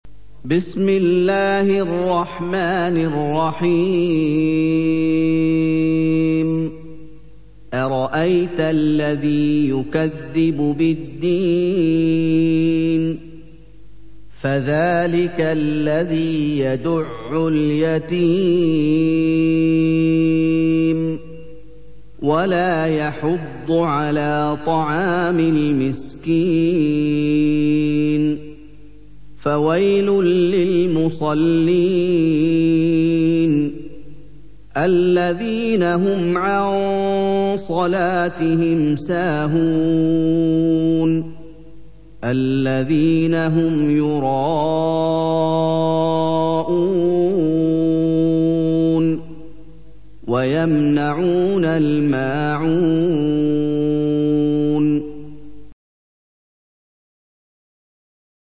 سُورَةُ المَاعُونِ بصوت الشيخ محمد ايوب